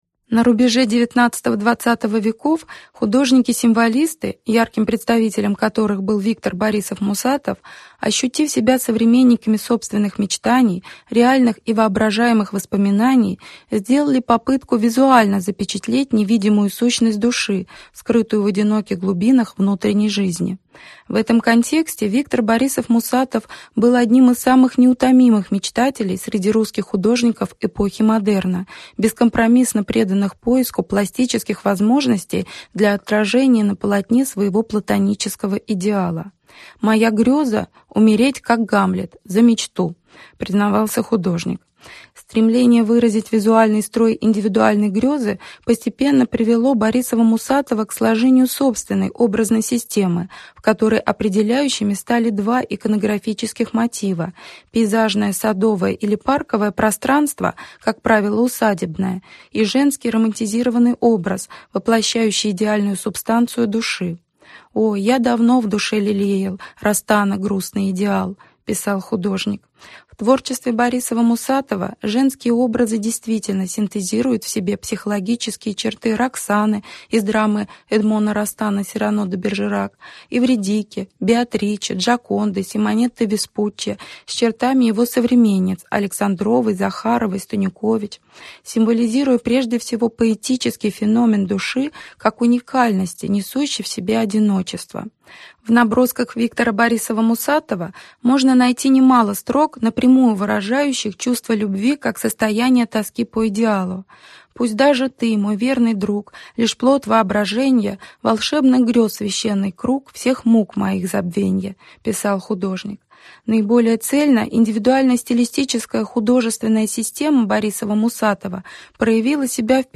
Лекция посвящена особенностям проявления поэтических принципов творческого мышления в искусстве выдающегося русского художника эпохи модерна Виктора Эльпидифоровича Борисова-Мусатова (1870–1905).